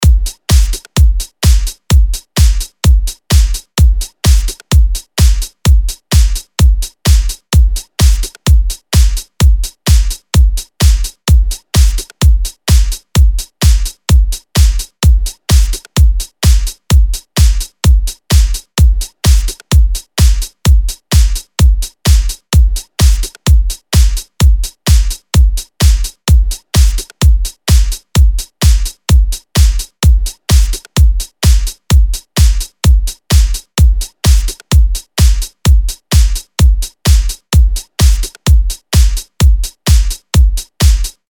LP 242 – DRUM LOOP – DANCE – 128BPM